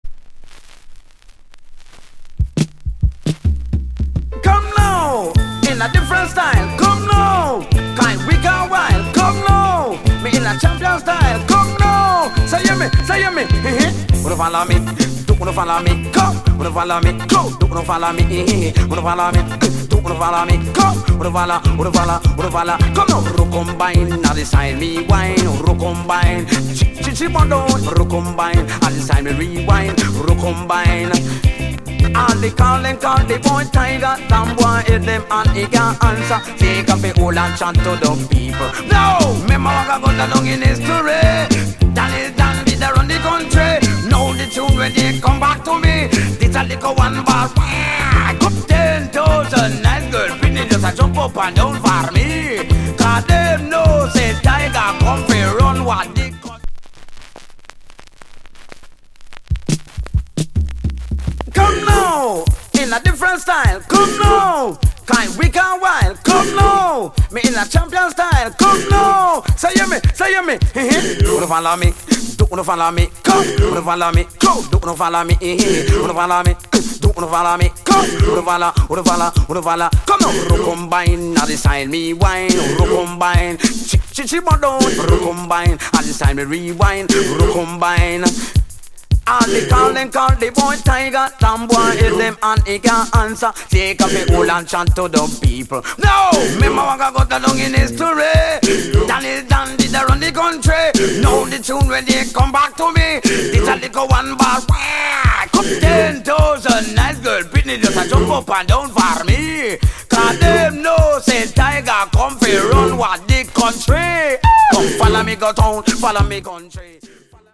* Calypso風リズムのHit Tune/B-side RemixこちらもGood